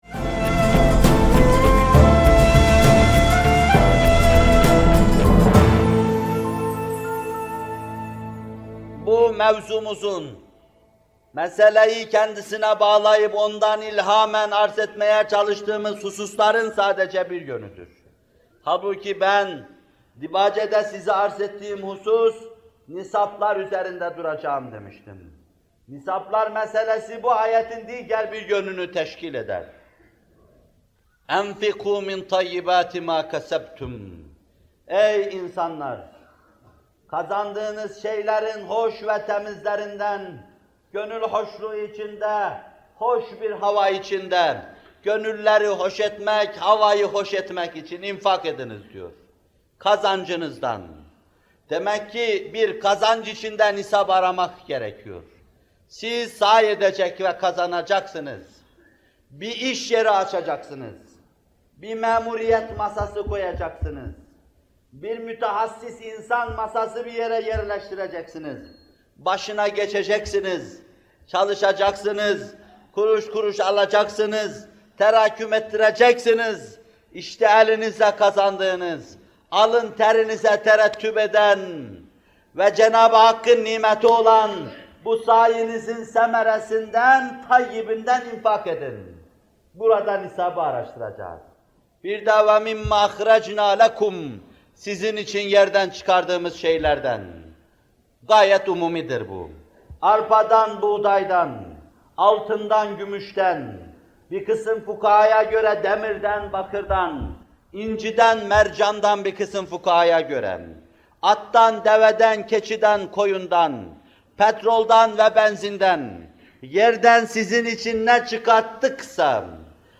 Zekat Vaazları – Zekât Verilecek Malların Özellikleri ve Miktarları Nedir, Kimlere Verilir?